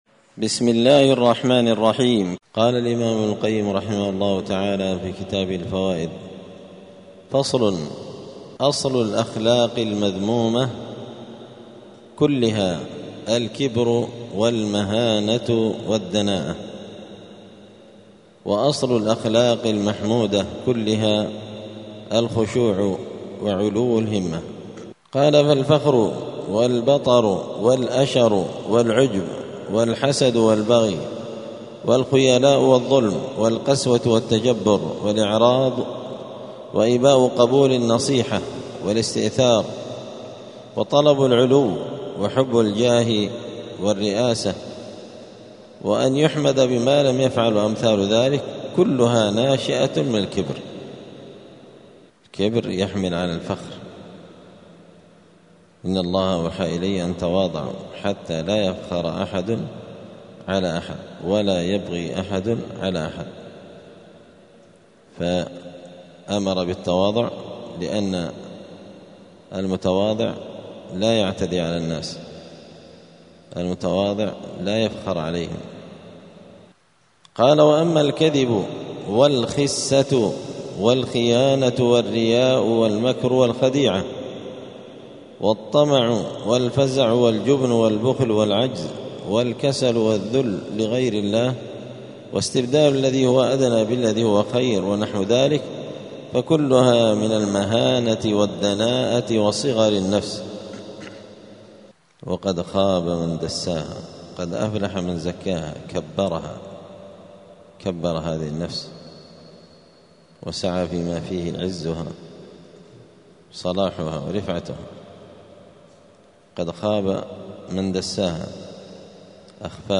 *الدرس السابع والسبعون (77) {فصل: أصل الأخلاق المذمومة كلها الكبر والعجب}*